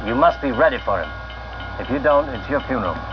funeral.wav